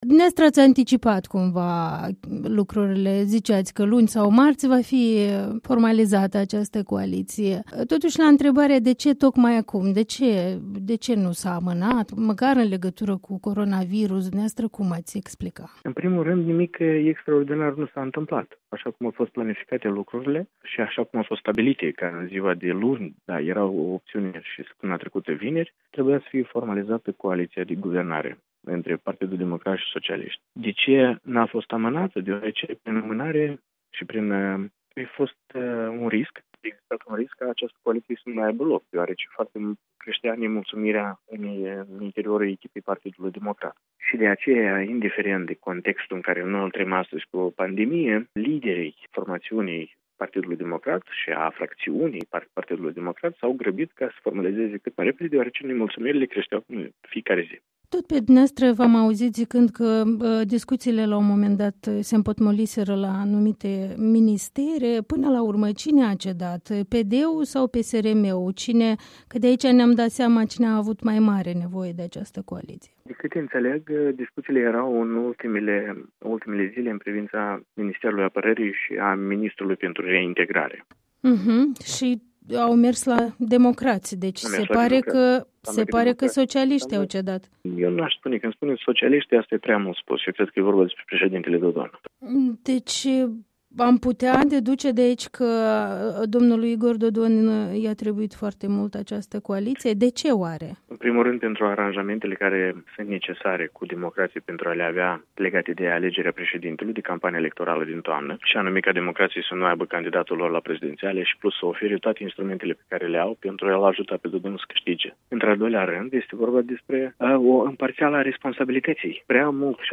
Andrian Candu, fost democrat plecat împreună cu un grup de colegi din formaţiune cu câteva săptămâni înainte de această coalizare, afirmă într-un interviu în exclusivitate la Europa Liberă că cel care a cedat este de fapt Igor Dodon, care a obţinut în schimb promisiunea că demcoraţii nu vor avea un candidat propriu la prezidenţialele din toamnă.